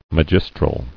[mag·is·tral]